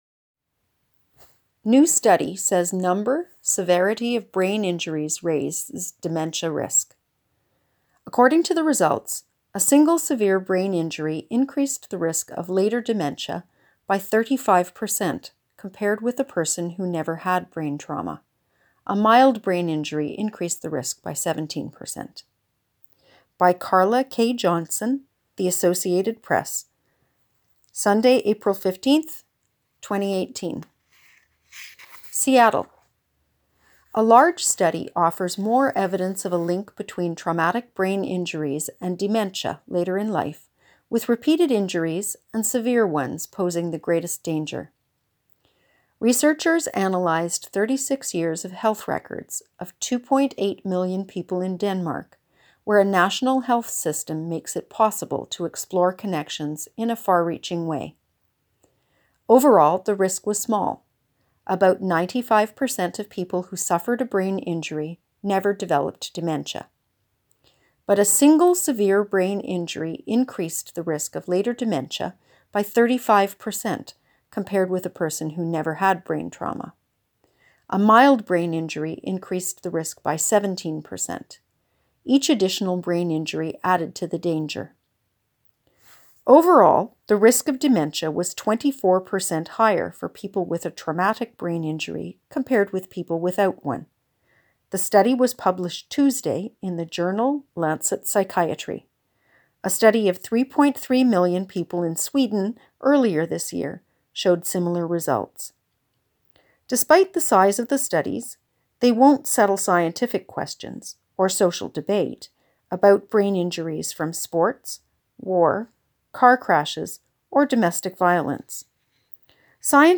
To read the full article in The Star, click the link below, or if you would prefer to have the article read aloud to you, simply click the play button below.